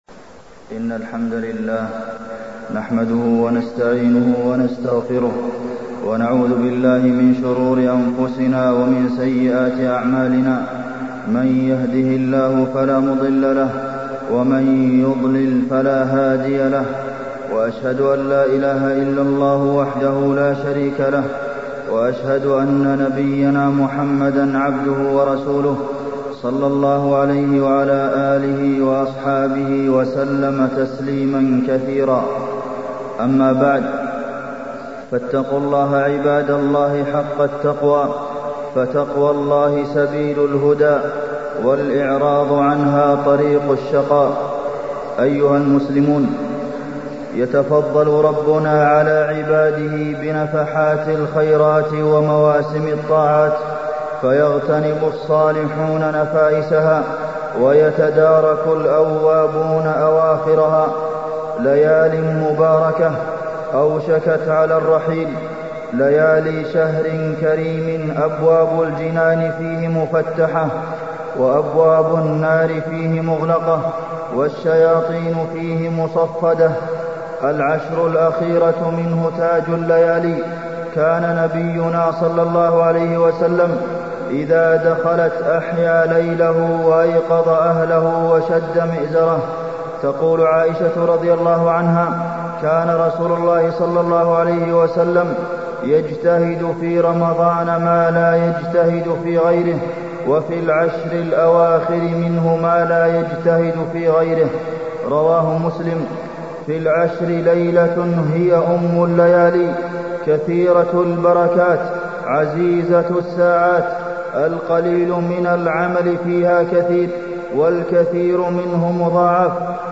تاريخ النشر ٢٢ رمضان ١٤٢٥ هـ المكان: المسجد النبوي الشيخ: فضيلة الشيخ د. عبدالمحسن بن محمد القاسم فضيلة الشيخ د. عبدالمحسن بن محمد القاسم العشر الأواخر من رمضان